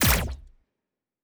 pgs/Assets/Audio/Sci-Fi Sounds/Weapons/Weapon 04 Shoot 1.wav at 7452e70b8c5ad2f7daae623e1a952eb18c9caab4
Weapon 04 Shoot 1.wav